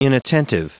Prononciation du mot inattentive en anglais (fichier audio)
Prononciation du mot : inattentive